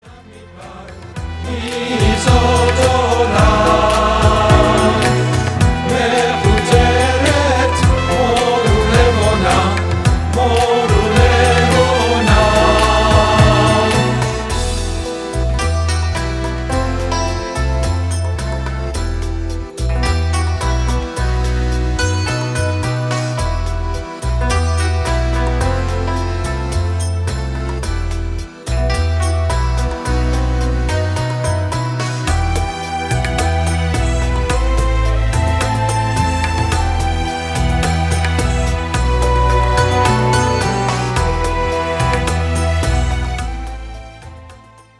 Indian musical instruments
Israeli Folk Song